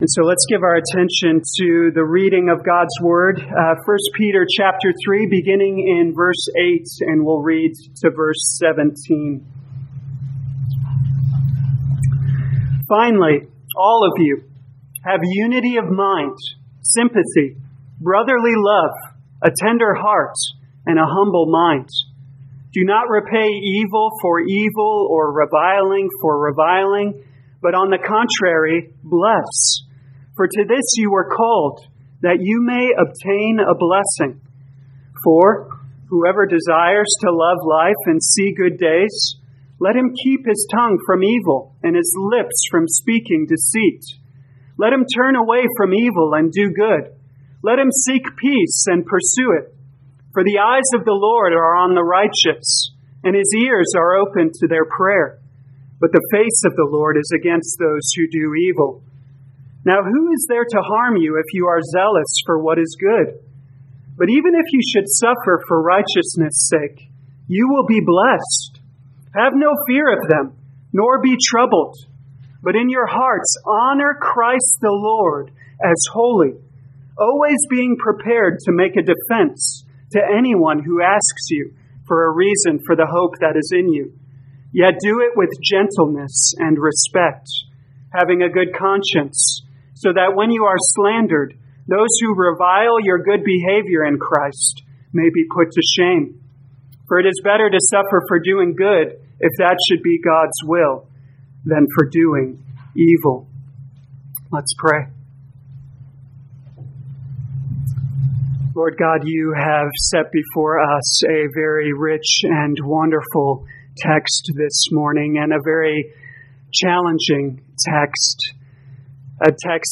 2021 1 Peter Humility Morning Service Download